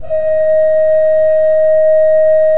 Index of /90_sSampleCDs/Propeller Island - Cathedral Organ/Partition K/KOPPELFLUT R